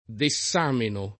vai all'elenco alfabetico delle voci ingrandisci il carattere 100% rimpicciolisci il carattere stampa invia tramite posta elettronica codividi su Facebook Dessameno [ de SS# meno ] (meglio che Dexameno [ dek S# meno ]) pers. m. stor.